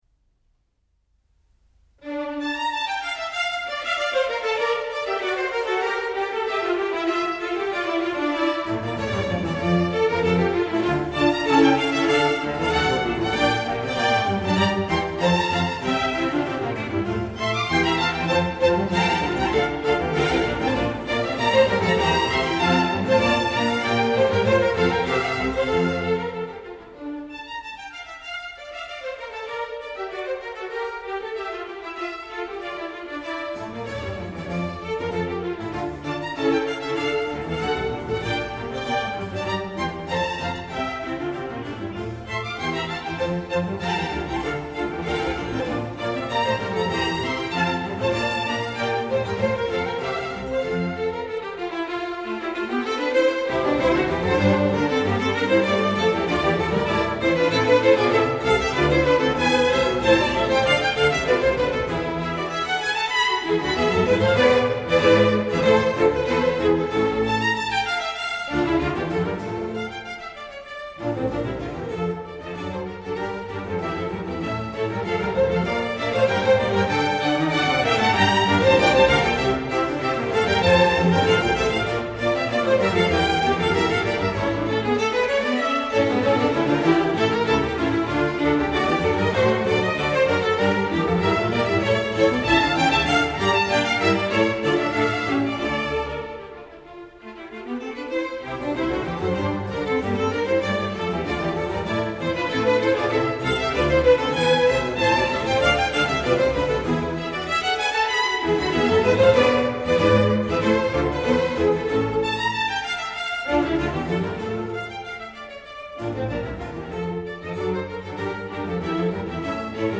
(English Chamber Orchestra conducted by R. Leppard) - complete
Allegro
2-10 Concerto No. 10 In D Min., Alle.mp3